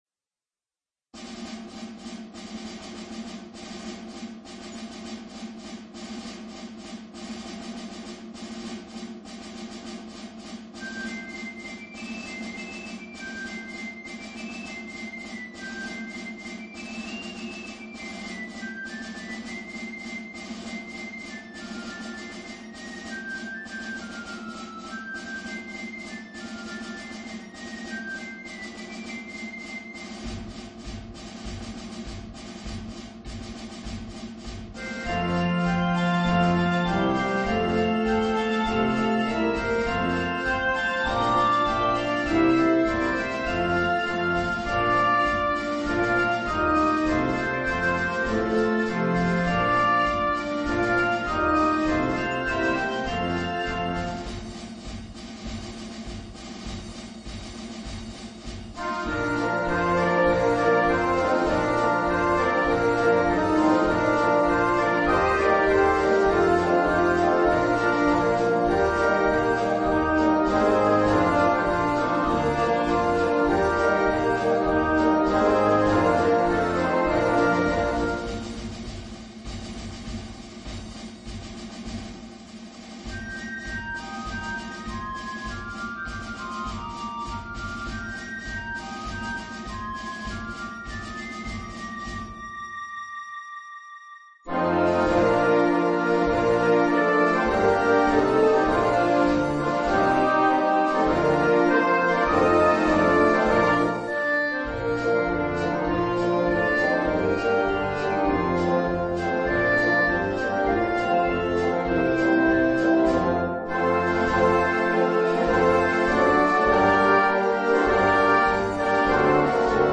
Voicing: Cnct Band